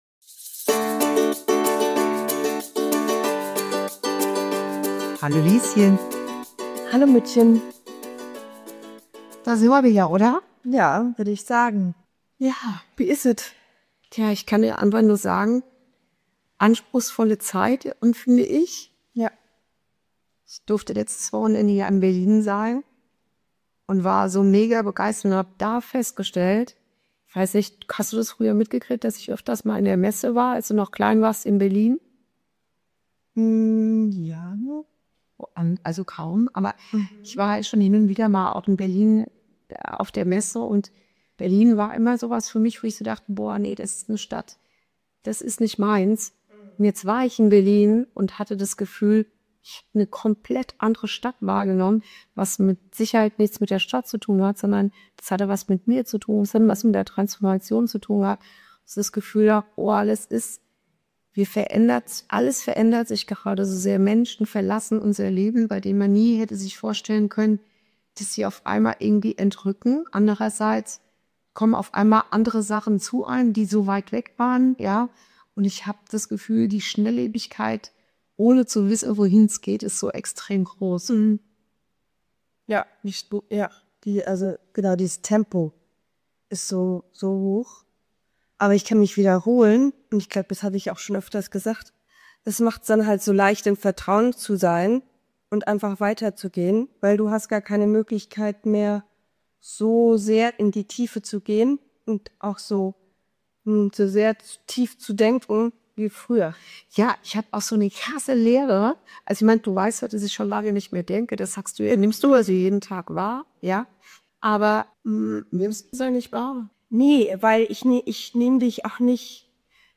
Ein Gespräch zwischen Mutter und Tochter